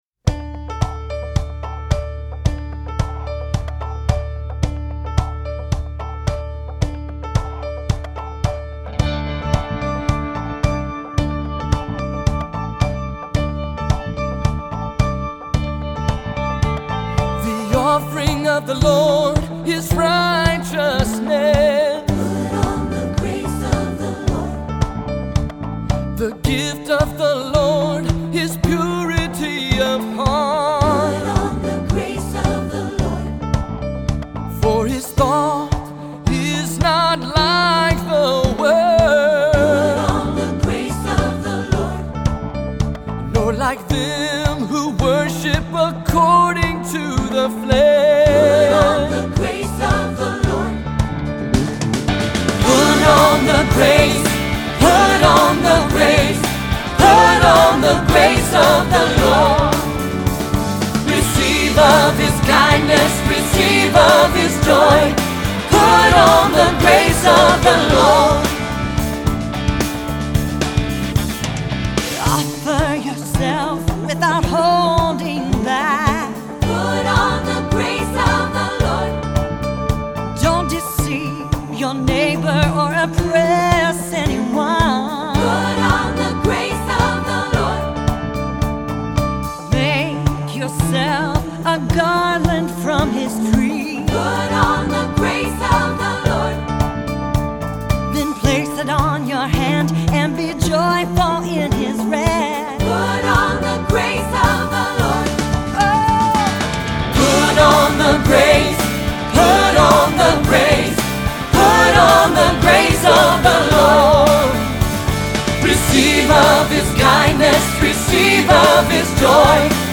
Summer Choir Anthems